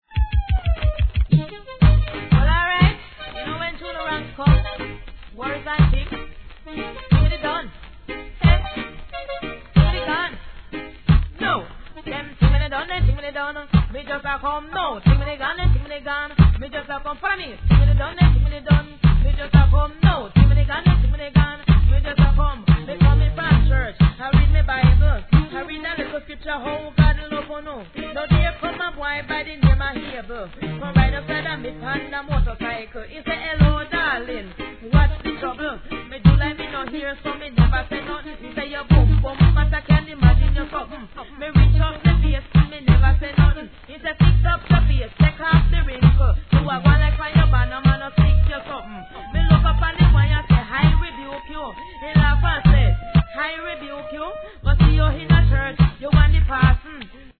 REGGAE
フィメールDeeJay!